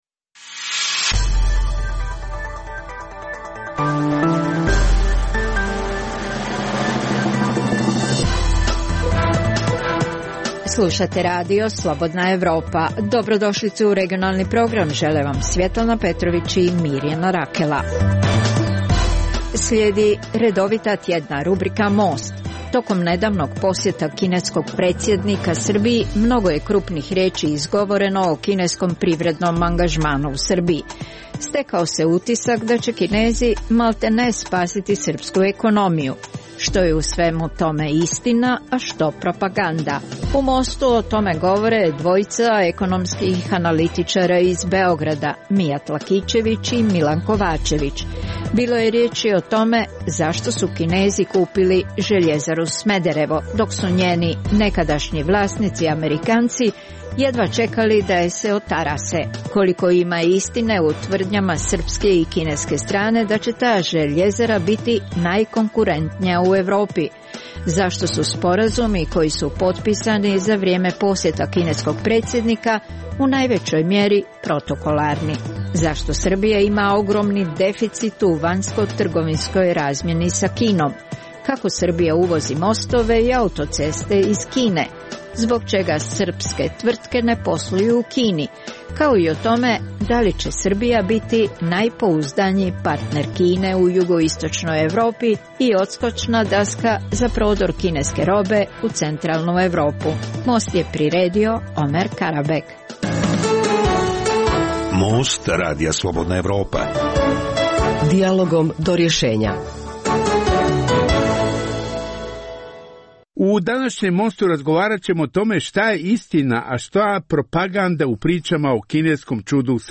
Emisija o dešavanjima u regionu (BiH, Srbija, Kosovo, Crna Gora, Hrvatska) i svijetu.